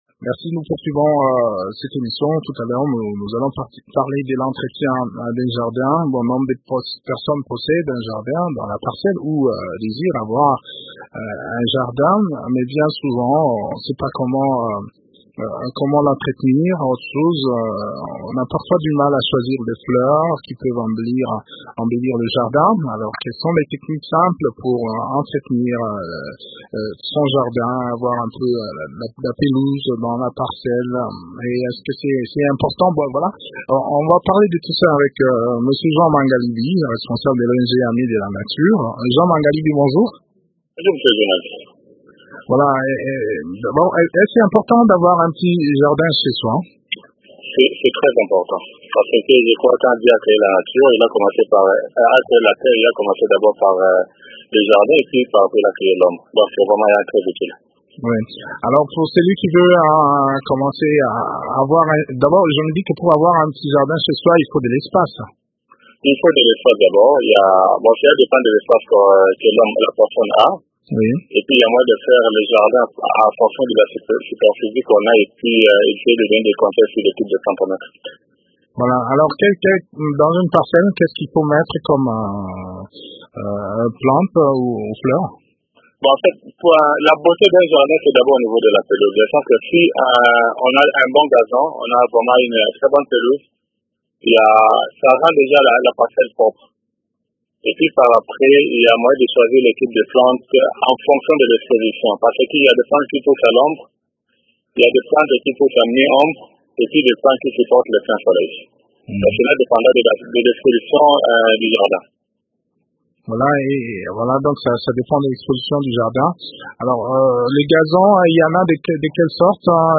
Quelles sont alors les techniques de culture et d’entretien d’un jardin des fleurs ? Eléments de réponse dans cet entretien